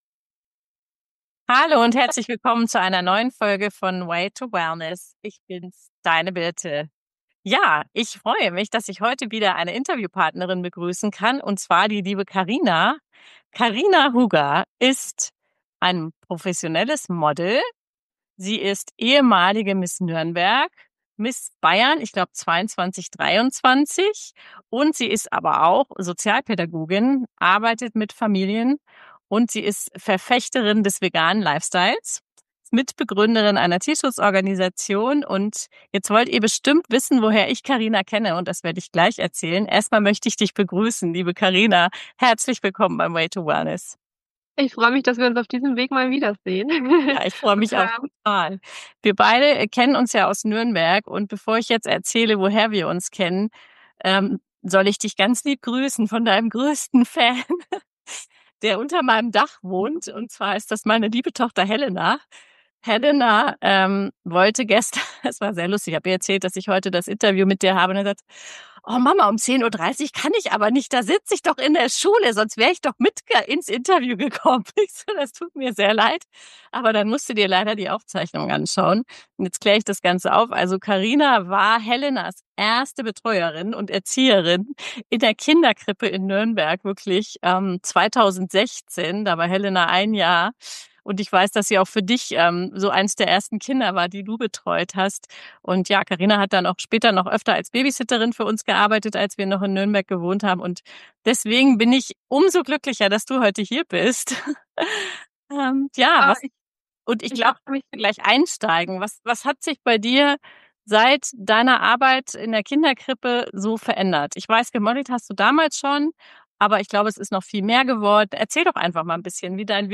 Freu dich auf ein ehrliches Gespräch über Sinnsuche, innere Balance und wie man Glamour mit Mitgefühl vereint.